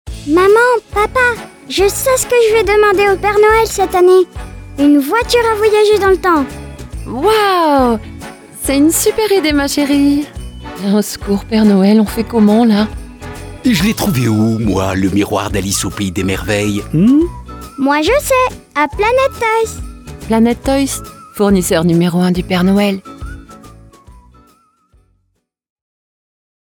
PUB/COMÉDIE Planet Toys
7 - 50 ans - Mezzo-soprano